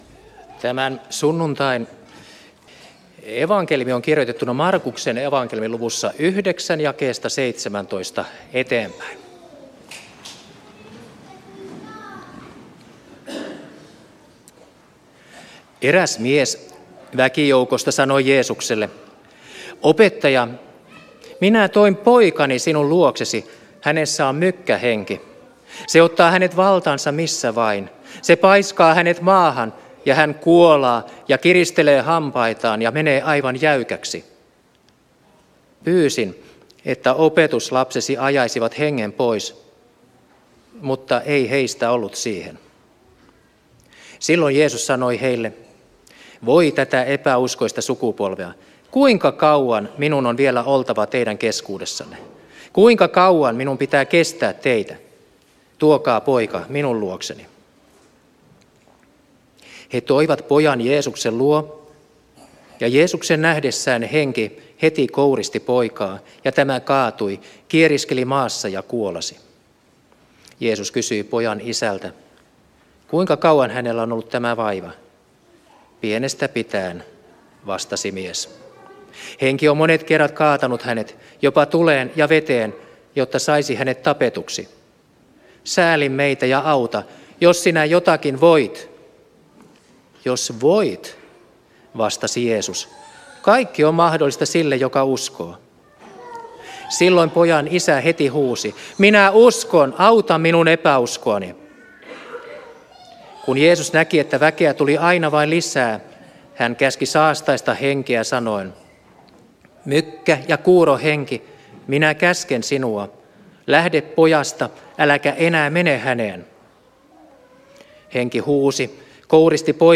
Turku